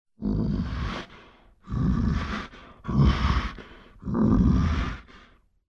Звуки гризли